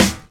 kits/RZA/Snares/GVD_snr (33).wav at main
GVD_snr (33).wav